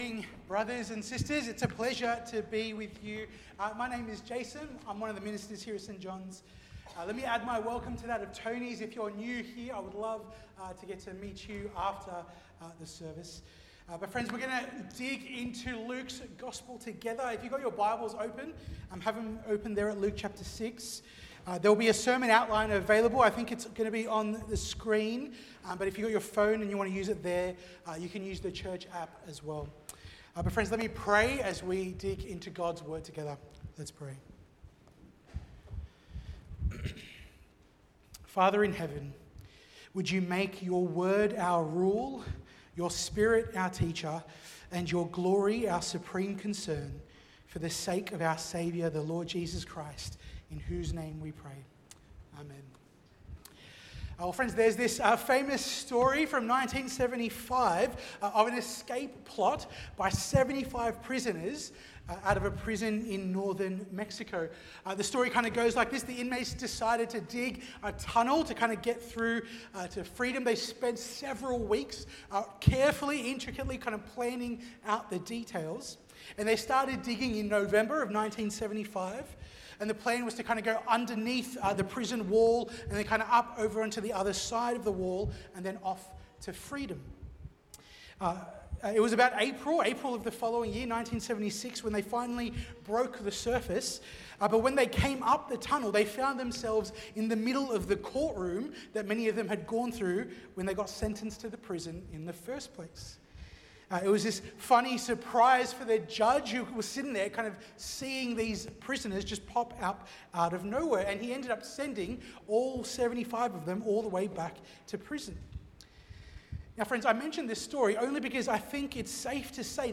Watch the full service on YouTube or listen to the sermon audio only.